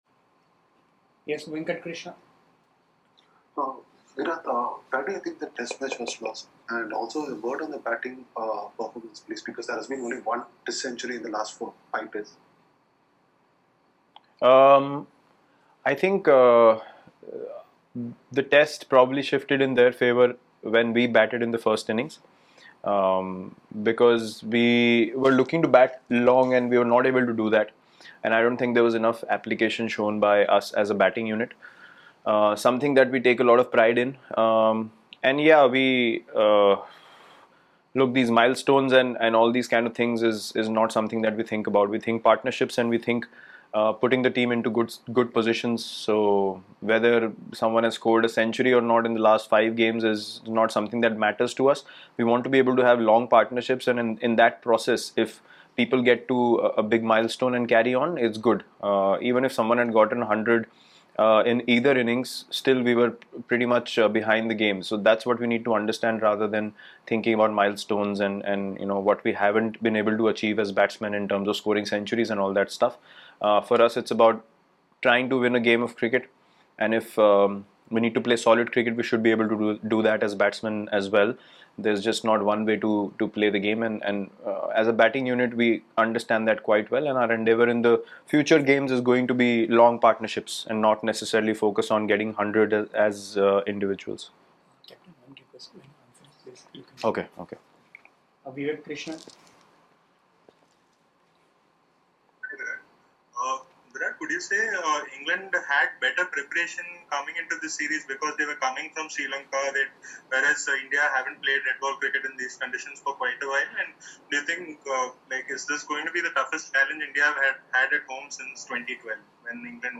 Virat Kohli speaks after 1st Test vs England